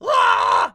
pigman_angry1.wav